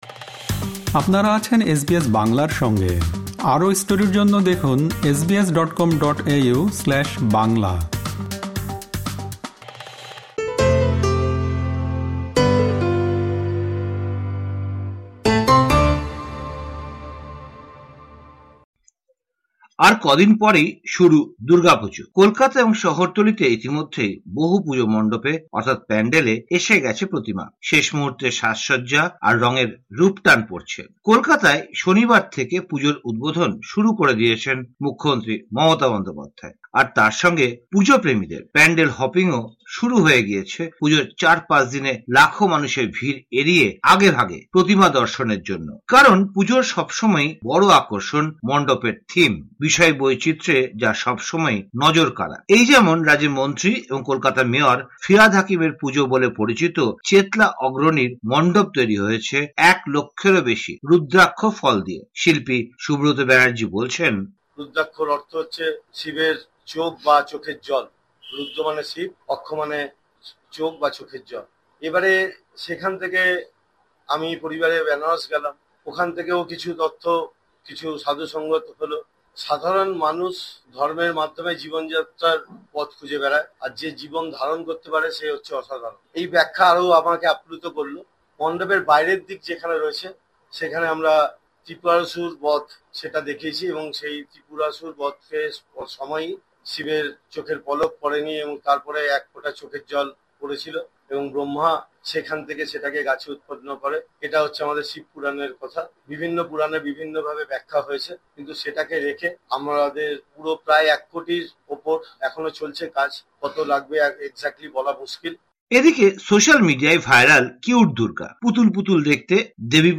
কলকাতার পুজো প্রস্তুতি নিয়ে এবারে শুনুন একটি বিশেষ প্রতিবেদন